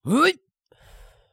人声采集素材/男2刺客型/CK起身1.wav